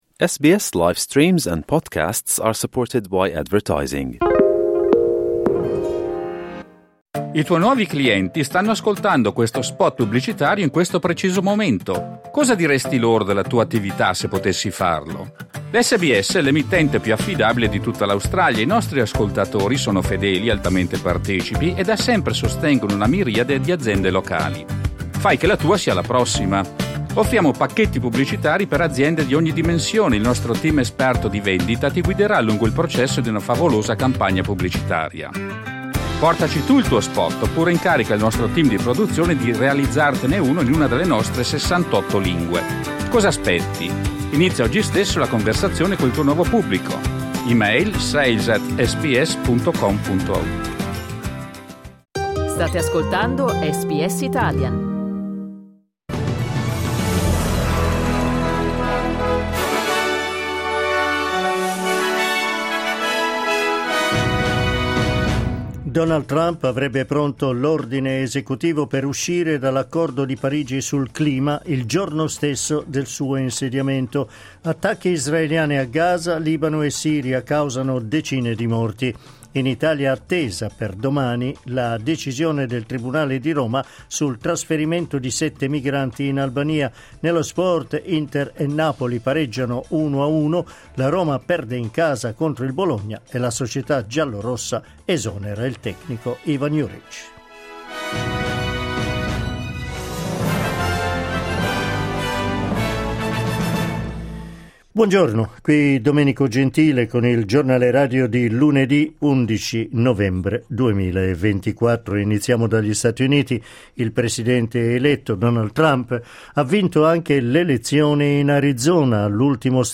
1 Giornale radio lunedì 11 novembre 2024 11:13